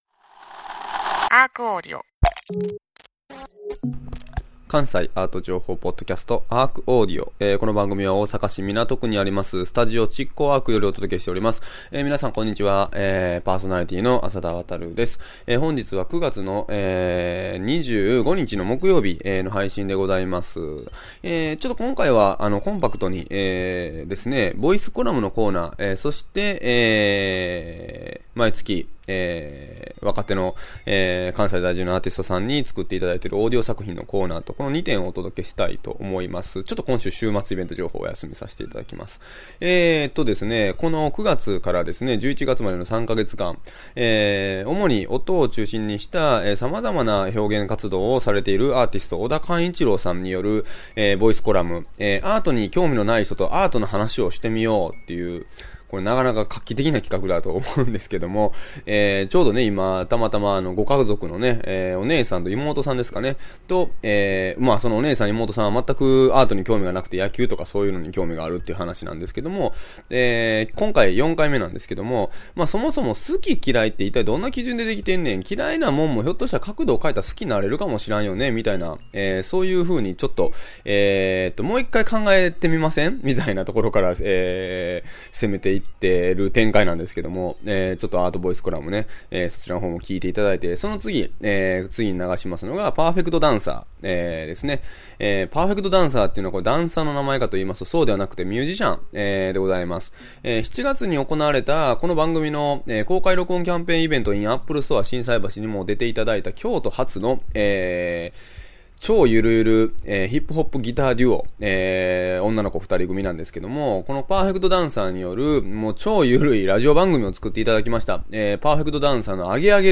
そしてオーディオ作品担当は、超脱力系ヒップホップギターデュオ：パーフェクトダンサーの登場！